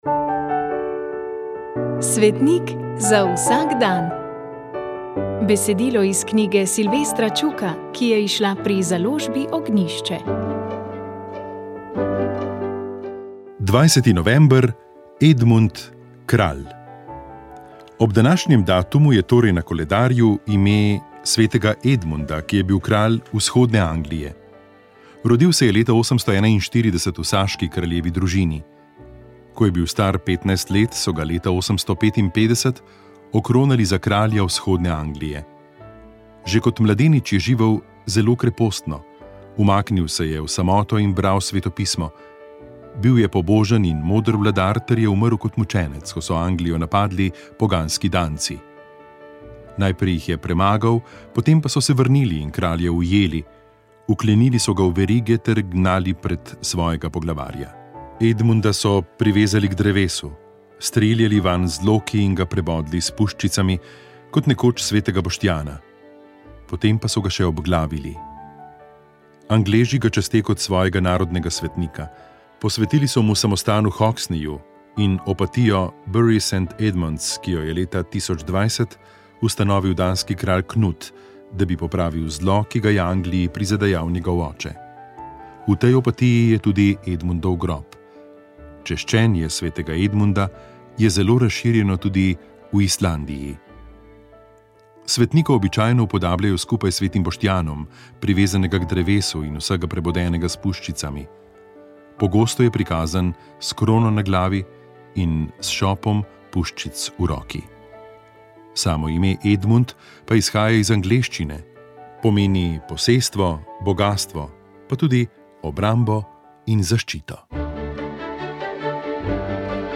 sodobna krščanska glasba